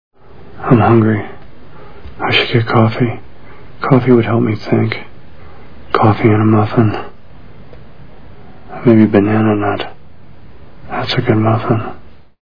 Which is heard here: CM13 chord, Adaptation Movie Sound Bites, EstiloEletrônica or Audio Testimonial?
Adaptation Movie Sound Bites